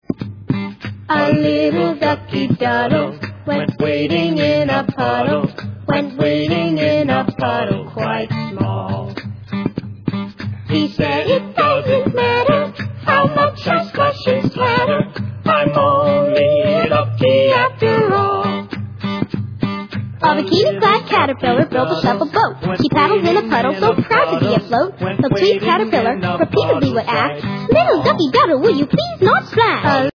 lively story-songs